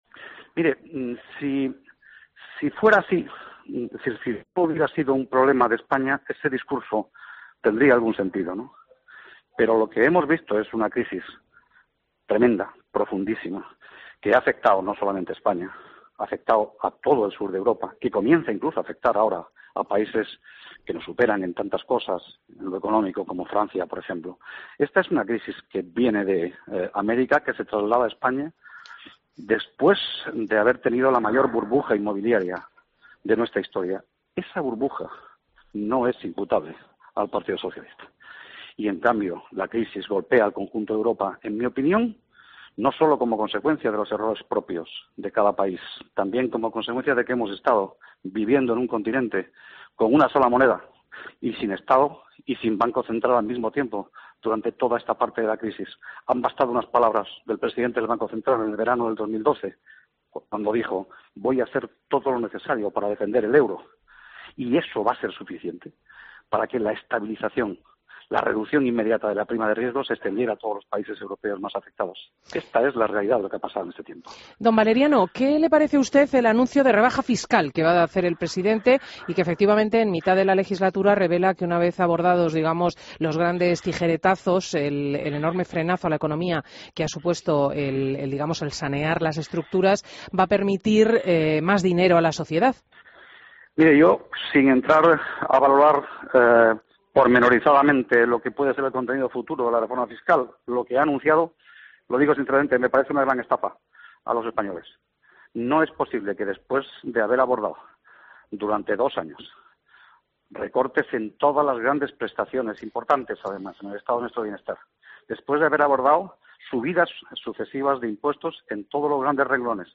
AUDIO: Entrevista a Valeriano Gómez en Fin de Semana COPE